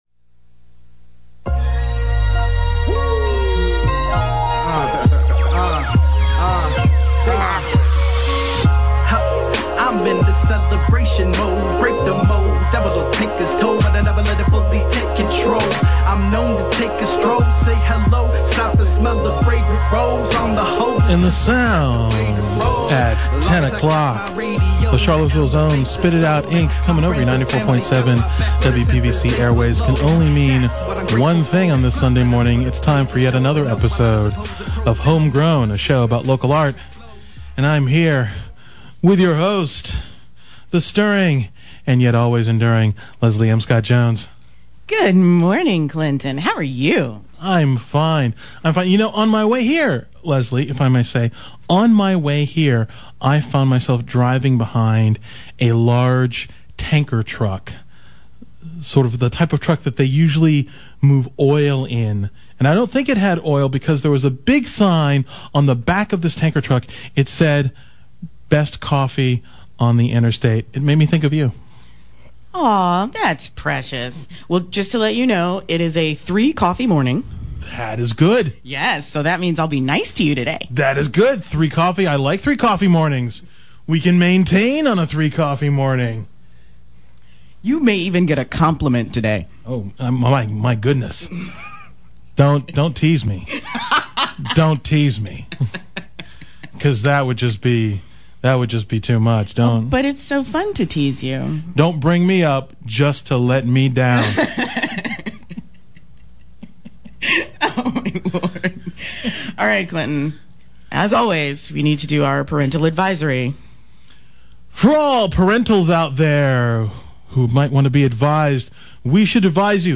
Home Grown is heard on WPVC 94.7 Sunday mornings at 10:00 a.m.